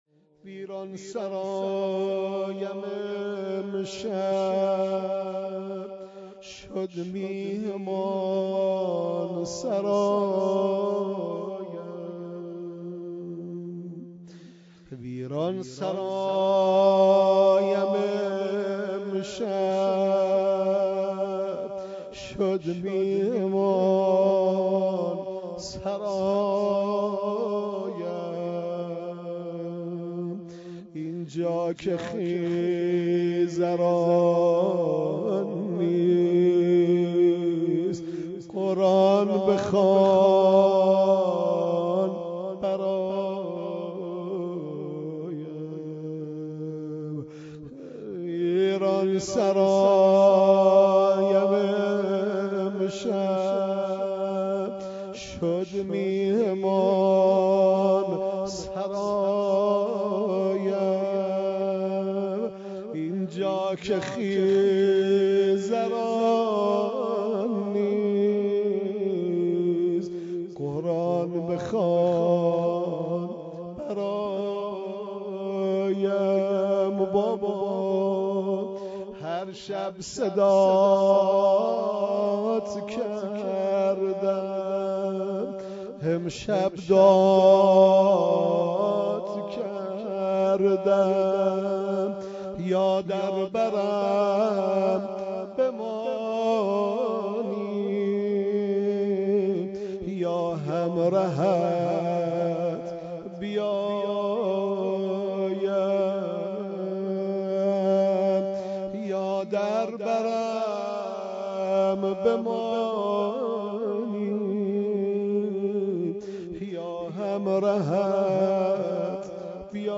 در هیئت بیت الحسین علیه السلام به مرثیه خوانی پرداخت.
بخشی از این مرثیه خوانی تقدیم مخاطبان می‌شود: